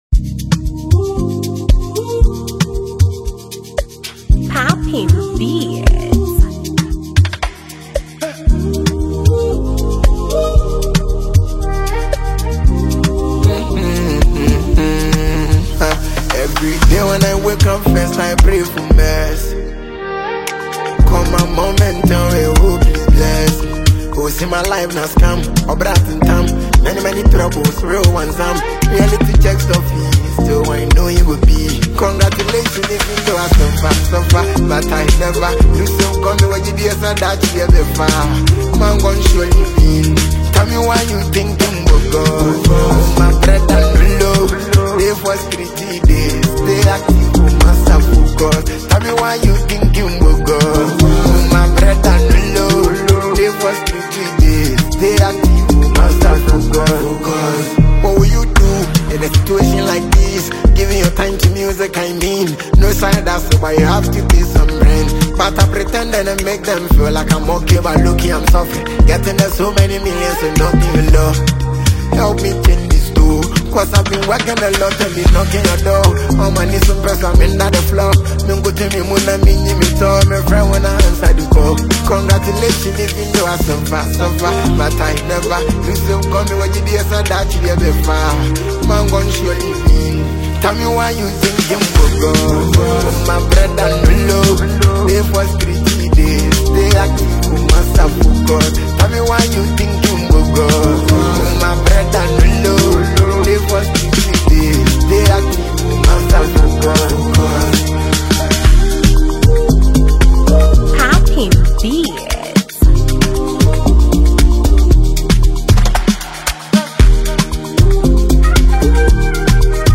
Ghana Music
Gritty and Unapologetic New Single
Afro-fusion and Hip-Hop
raspy, authoritative delivery.